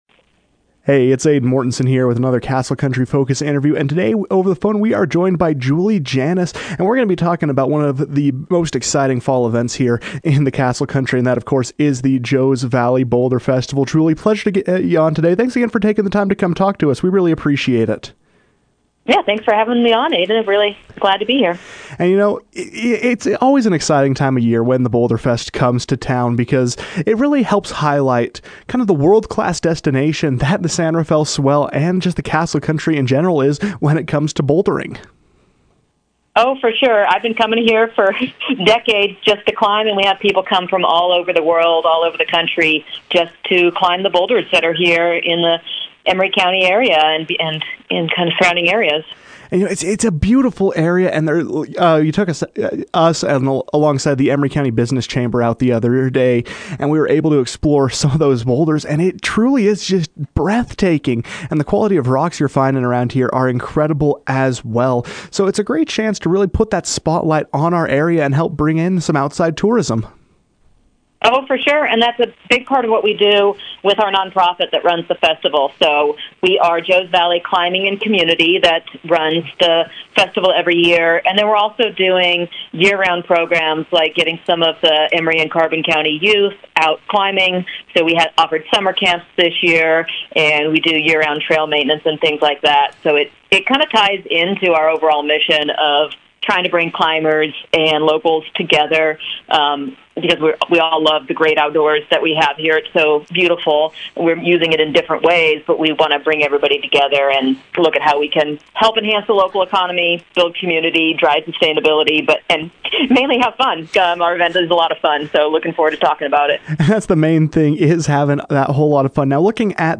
KOAL News As the trees change into their fall hues